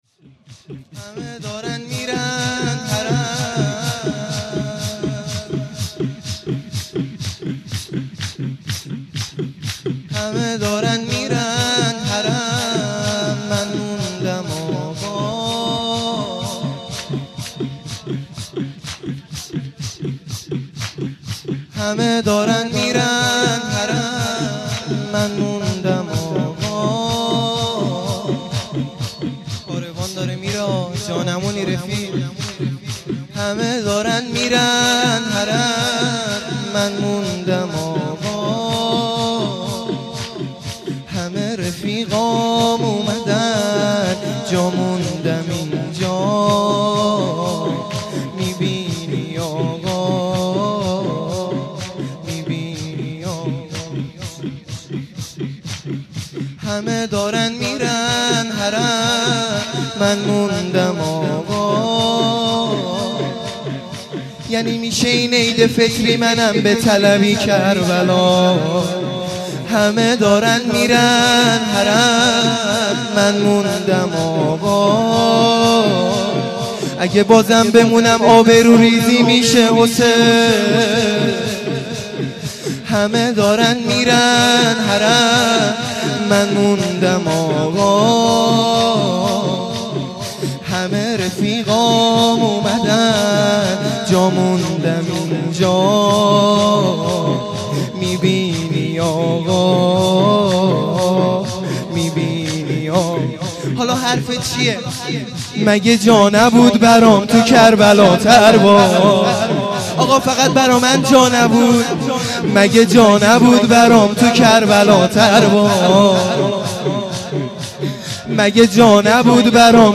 0 0 شور